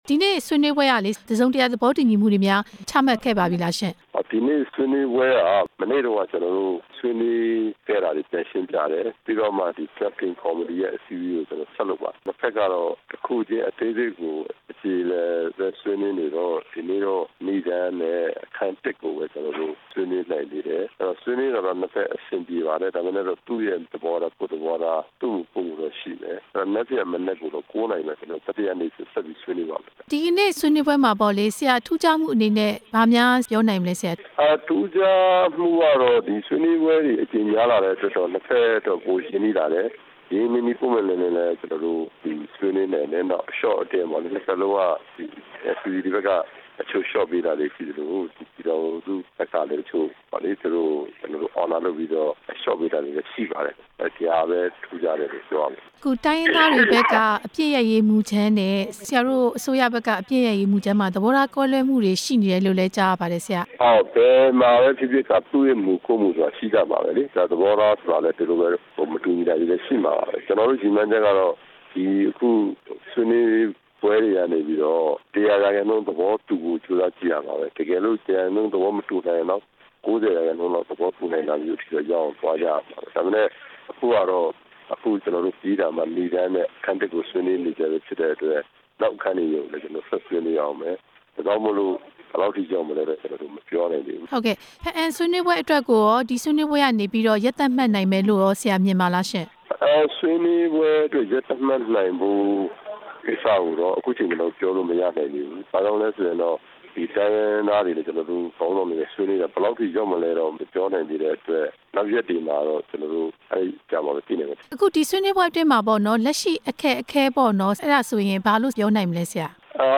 တစ်ပြည်လုံး အပစ်ခတ်ရပ်စဲရေးအတွက် အစိုးရနဲ့ တိုင်းရင်းသား လက်နက်ကိုင်အဖွဲ့တွေရဲ့ ဆွေးနွေးပွဲမှာ ညှိနှိုင်းမှုတွေ မပြီးပြတ်သေးတဲ့အတွက် မနက်ဖြန် တစ်ရက် ထပ်တိုးလိုက်ကြောင်း ဆွေးနွေးပွဲအပြီး ကျင်းပတဲ့ ဒီနေ့ညနေပိုင်း သတင်းစာရှင်းလင်းပွဲမှာ နှစ်ဖက်ခေါင်းဆောင်တွေက ပြောကြားလိုက်ကြပါတယ်။